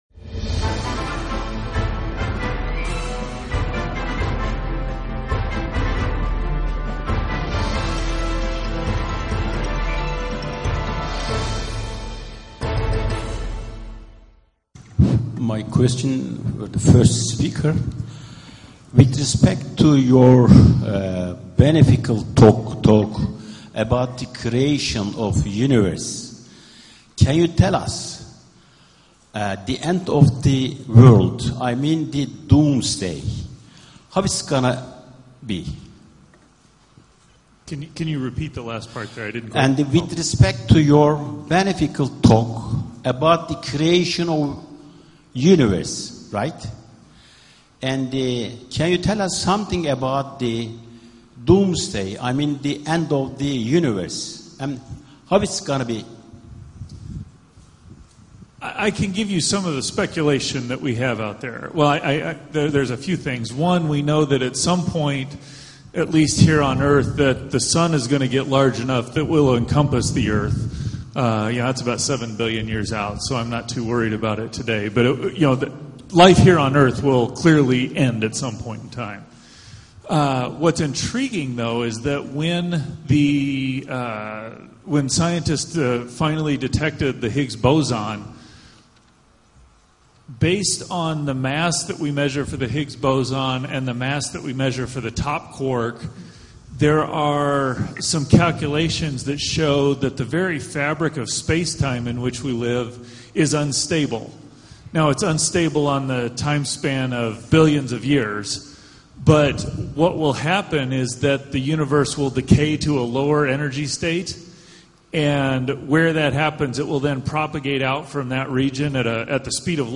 A9 TV | The Q&A session of the International Conference on the Origin of Life and The Universe held by TBAV (Technics & Science Research Foundation) in Conrad Istanbul Bosphorus Hotel, August 24th 2016 – Part 2